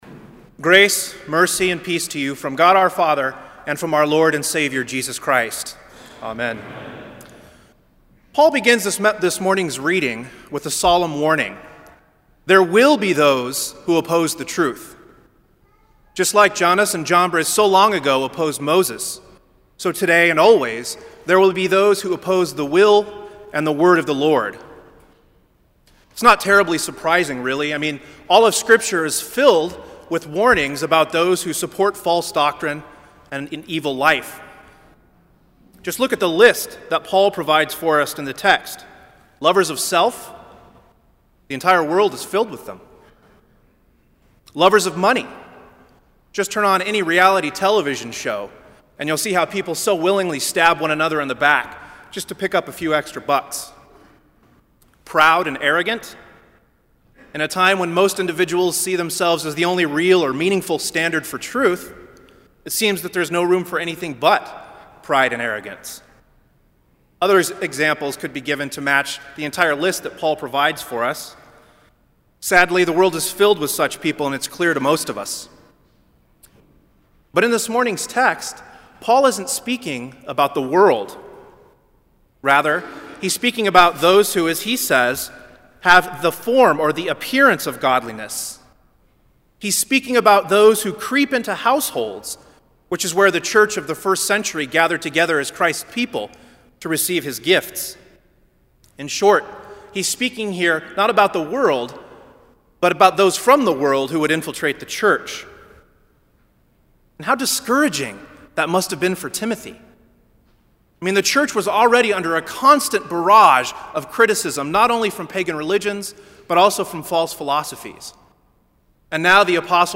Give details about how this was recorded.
Kramer Chapel Sermon - January 31, 2007